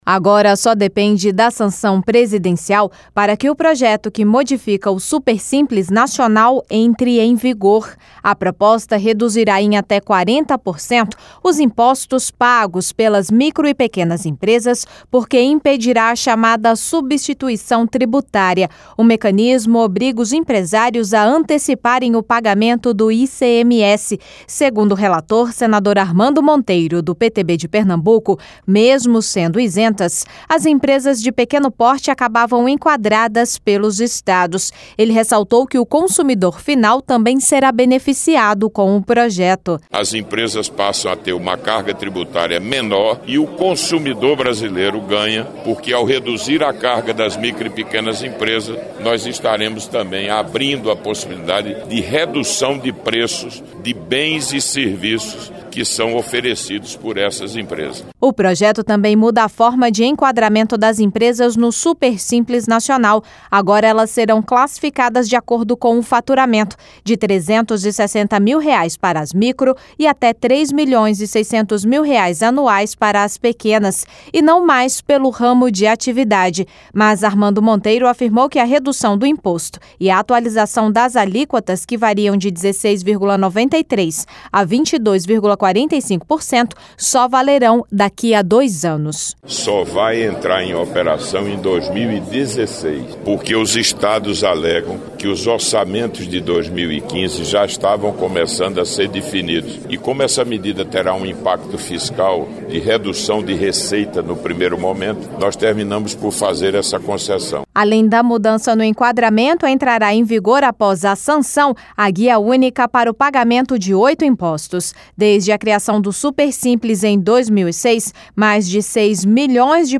Senador Armando Monteiro